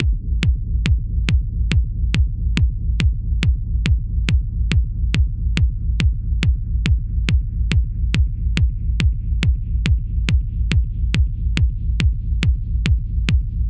I’ve added a low pass filter to the chain, with a little resonance. I’m making use of the remaining high frequencies to sweep around, adding a subtle building sensation to the sound.